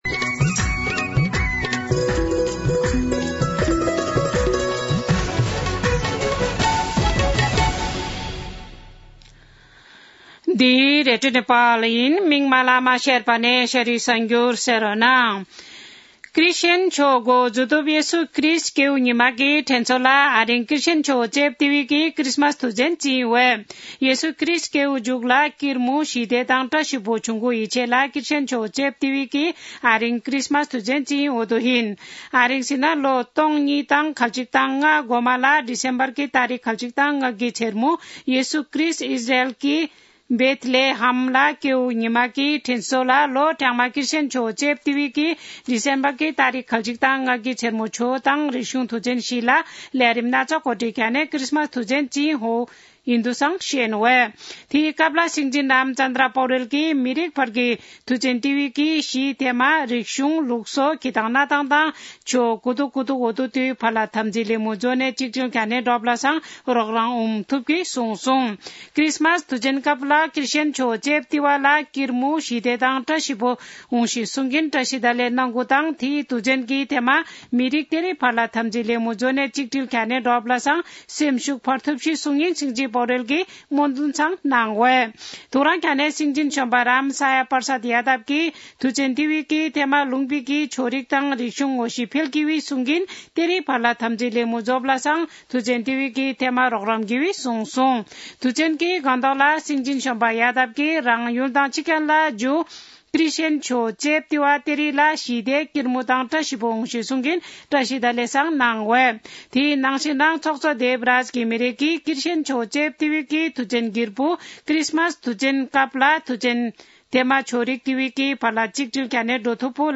शेर्पा भाषाको समाचार : ११ पुष , २०८१
Sherpa-News-4.mp3